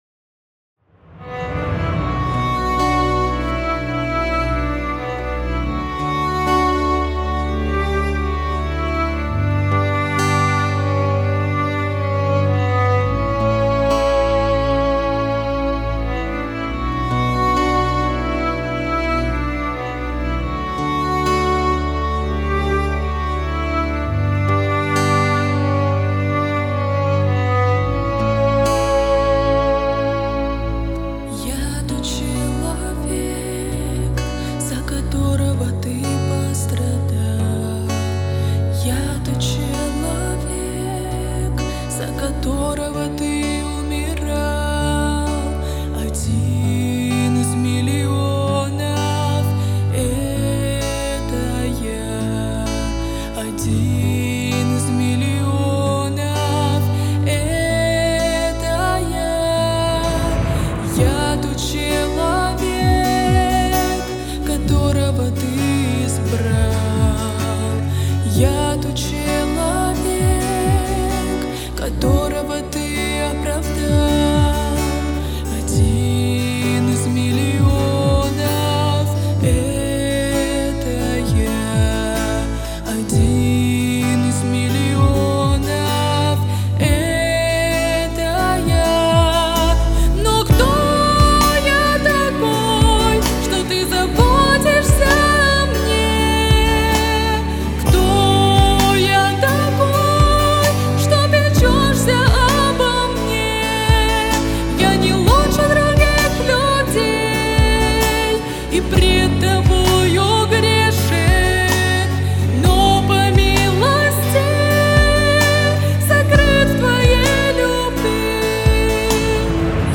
88 просмотров 224 прослушивания 7 скачиваний BPM: 130